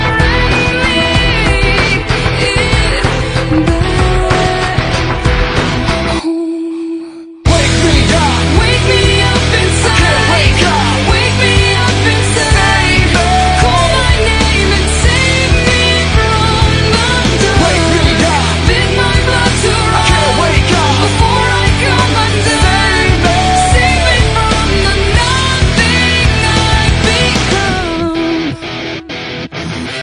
Alucard Luckybox Voice Lines sound effects free download
Alucard Luckybox - Voice Lines + Highlights